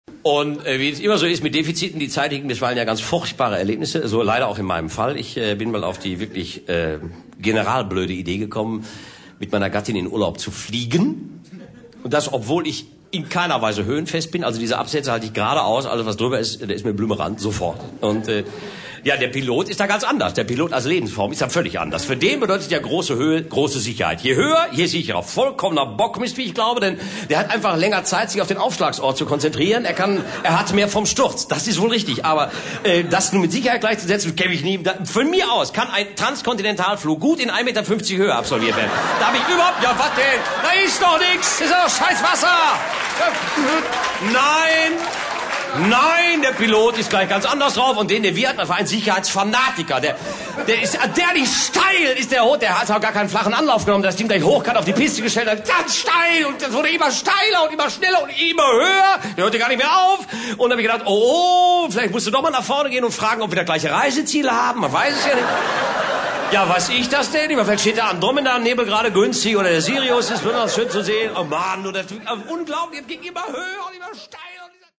Live-Mitschnitt
Jochen Malmsheimer (Sprecher)
Schlagworte Kabarett • Neues aus der Anstalt • Poesie • Wortgewalt • Wortwitz